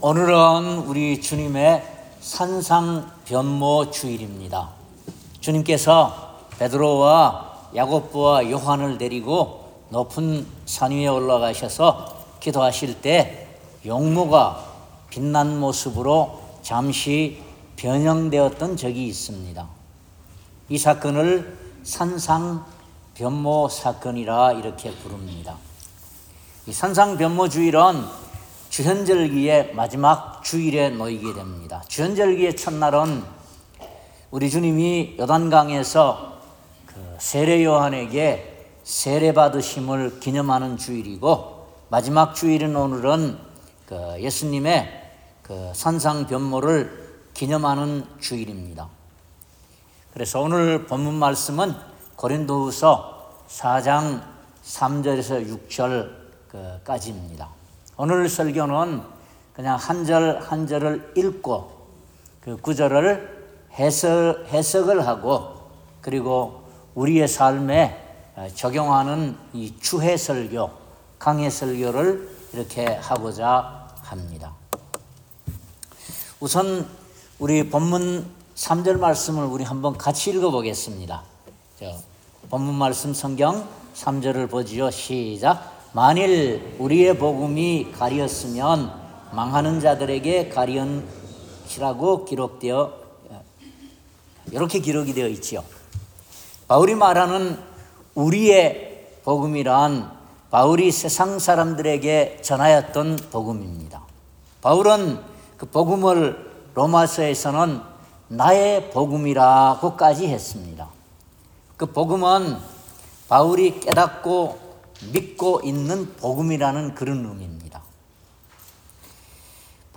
3-6 절 Service Type: 주일예배 우리 주님께서 베드로와 야고보와 요한을 데리고 높은 산에 올라가셔서 기도하실 때에 용모가 해와 같이 빛난 모습으로 잠시 변형되었던 적이 있었습니다.